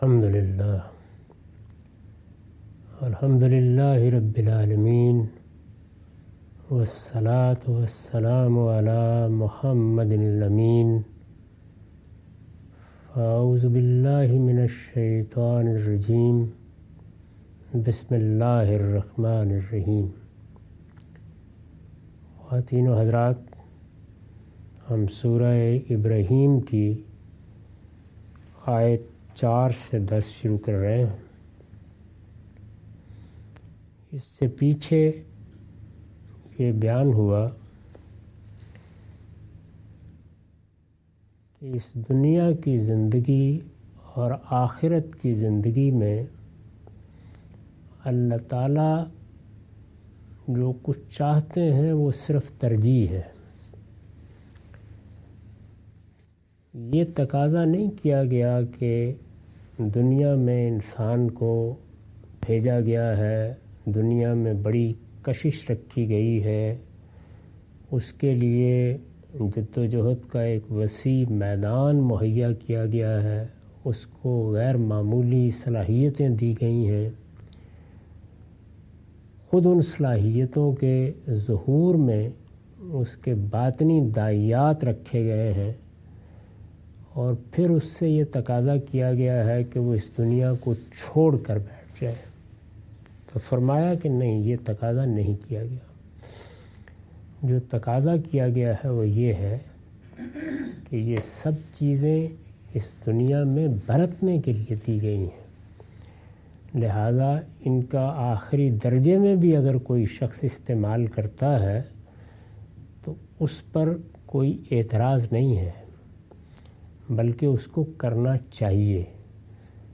Surah Ibrahim- A lecture of Tafseer-ul-Quran – Al-Bayan by Javed Ahmad Ghamidi. Commentary and explanation of verses 04-05.